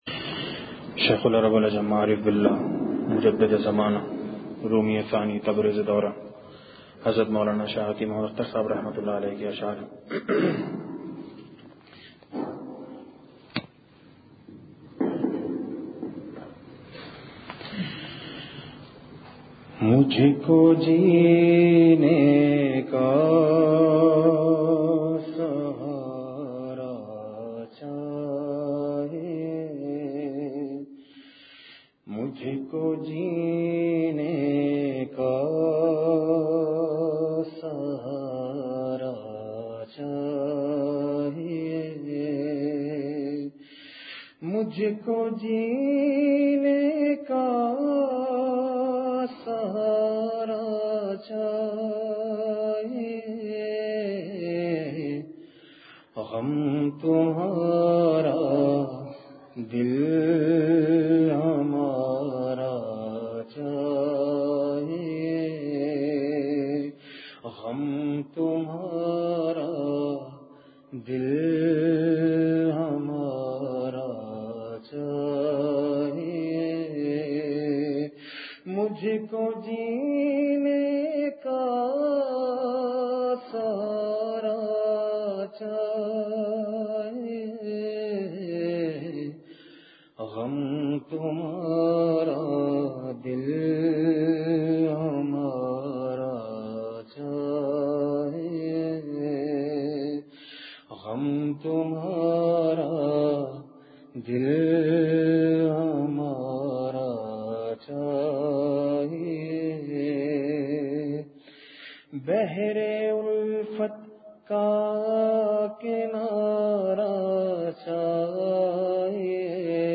Please download the file: audio/mpeg مجلس محفوظ کیجئے اصلاحی مجلس کی جھلکیاں بمقام۔
بہت ہی درد بھری اور تقریبا بارہ منٹ کی دعا۔